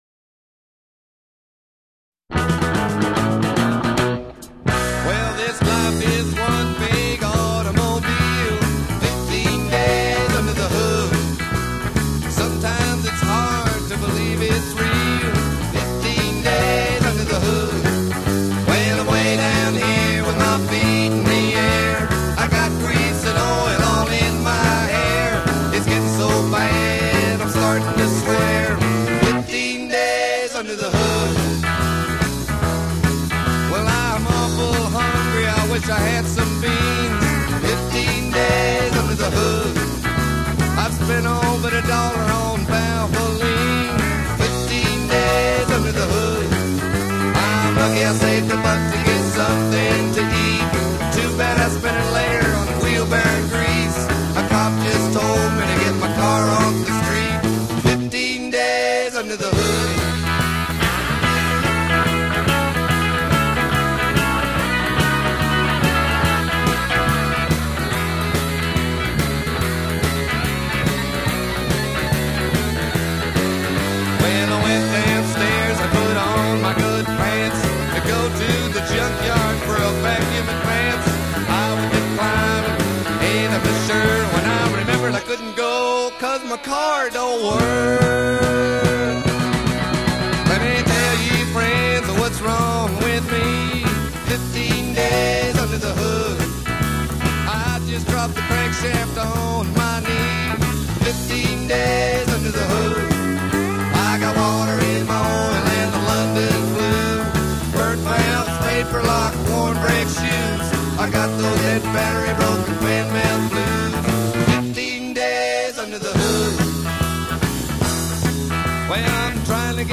country rock band